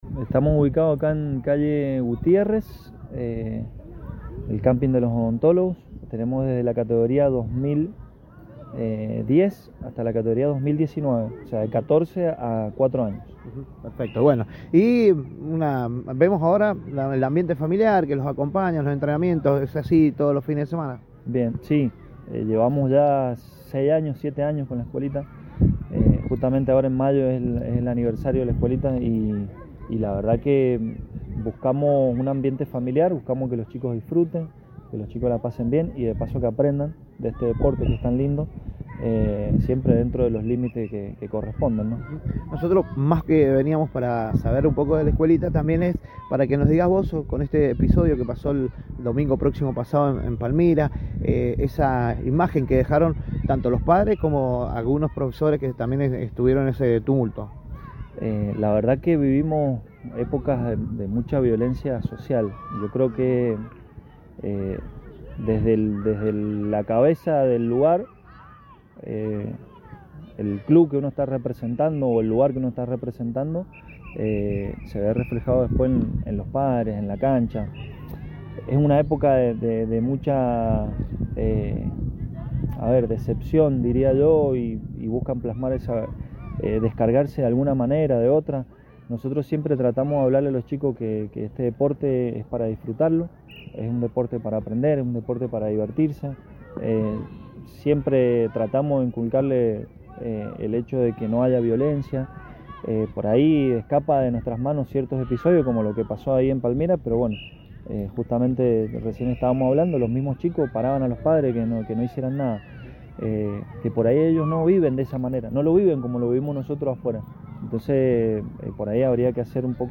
Entrevista del día: Escuela de fútbol infantil UDLA
La nota del día en el aire de Proyección 103, tuvo que ver con la actividad deportiva de los más chicos, y el crecimiento de nuestros entrenadores de la zona.